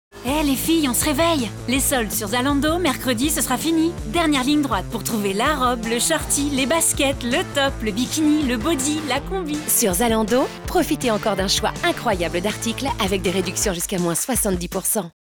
PUB RADIO
Voix comédie